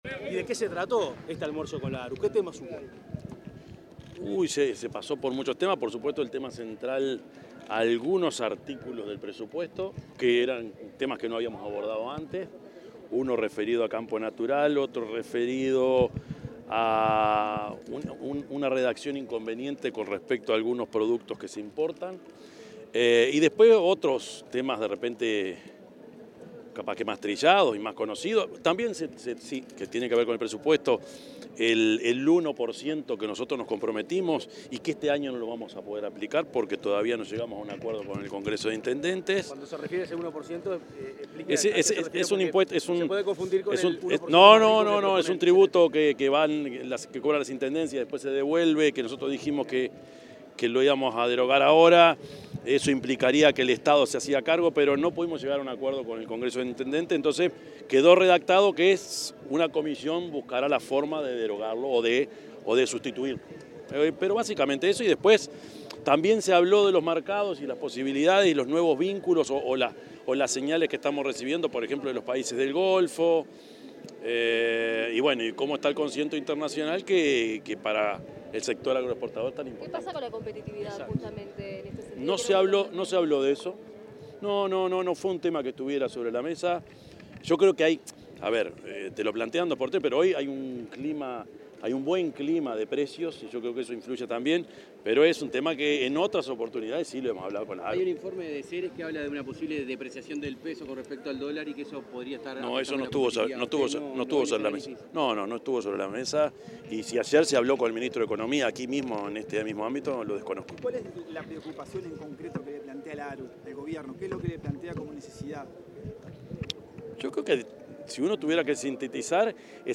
Declaraciones del presidente Yamandú Orsi en Expo Prado
Declaraciones del presidente Yamandú Orsi en Expo Prado 10/09/2025 Compartir Facebook X Copiar enlace WhatsApp LinkedIn Al finalizar la reunión con la junta directiva de la Asociación Rural del Uruguay (ARU) en la Expo Prado 2025, el presidente de la República, Yamandú Orsi, efectuó declaraciones a la prensa.